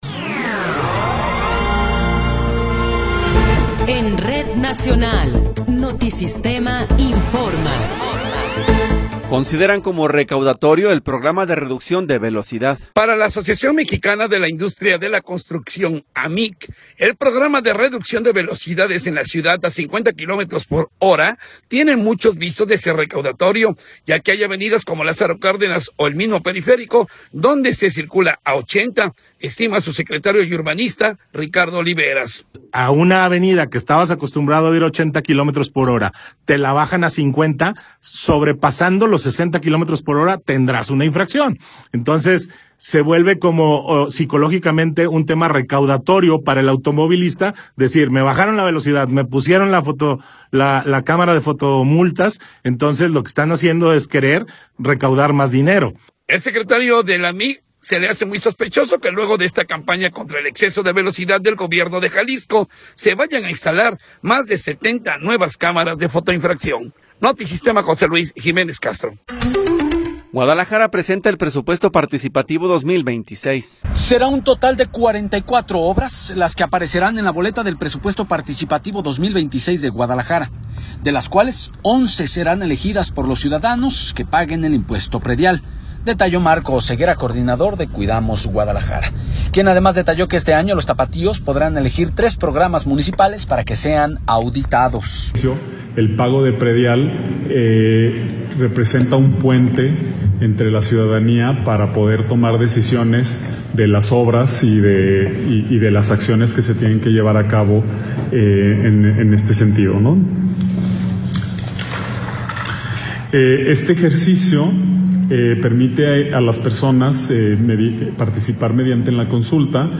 Noticiero 13 hrs. – 20 de Enero de 2026